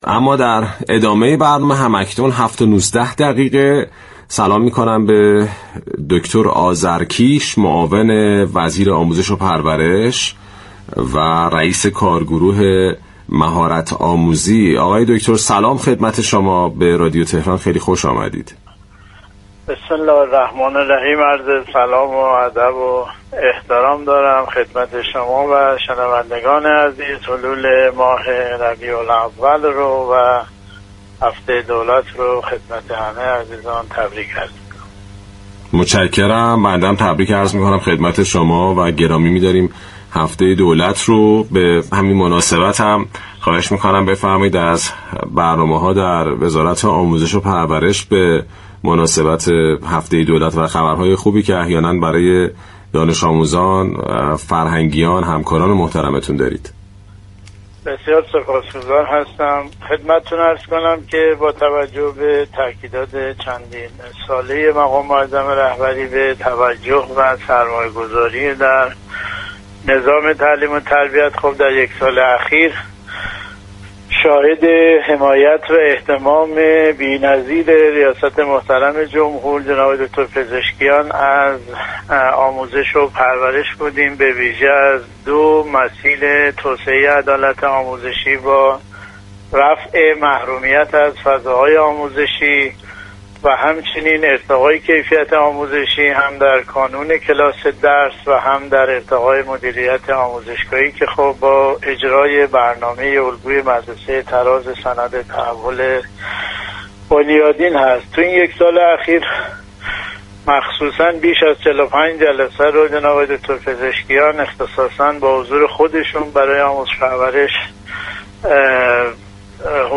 معاون وزیر آموزش و پرورش در گفت‌وگو با رادیو تهران از افتتاح هزاران فضای آموزشی جدید، گسترش هنرستان‌های جوار كارخانه، راه‌اندازی شبكه ملی یادگیری معلمان و اجرای طرح «ایران دیجیتال» برای آموزش هوش مصنوعی به دو میلیون دانش‌آموز خبر داد؛ طرحی كه در اجلاس جهانی جامعه اطلاعاتی 2025 به‌عنوان یكی از پنج پروژه برتر جهان معرفی شد.